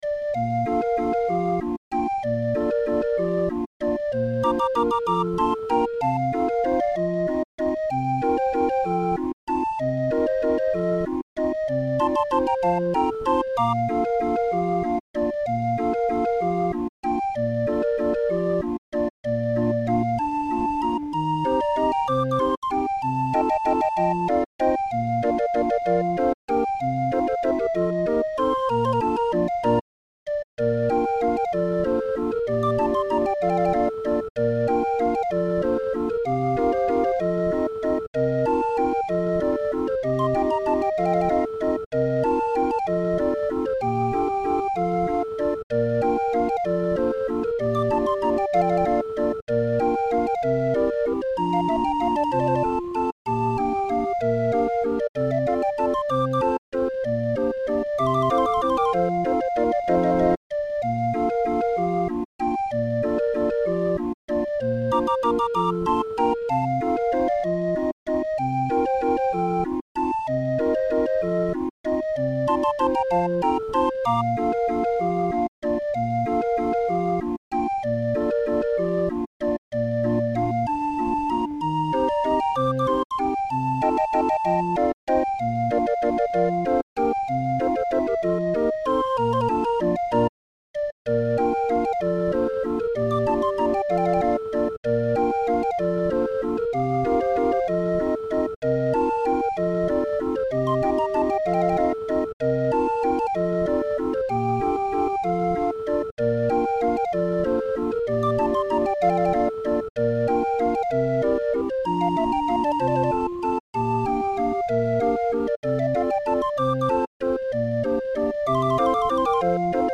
English waltz.